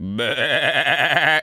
sheep_baa_bleat_09.wav